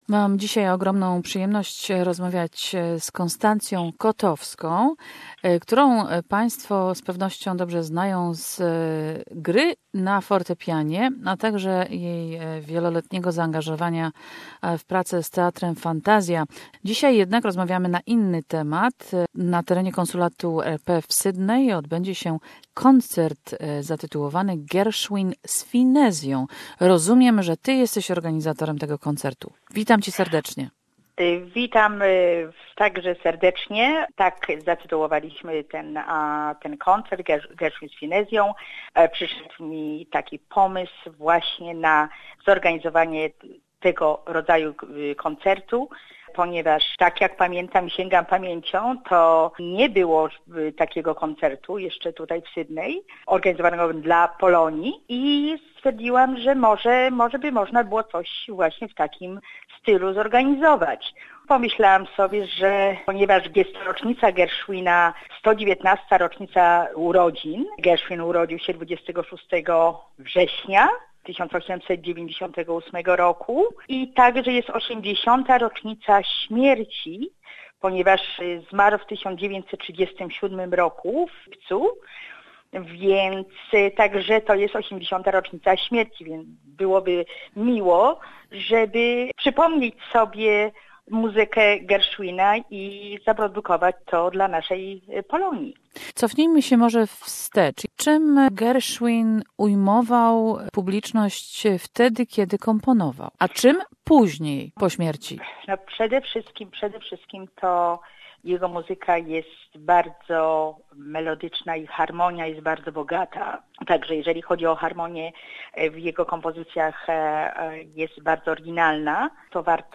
Gershwin classics presented by a violin, cello andpiano trio at the Polish Consulate in Sydney.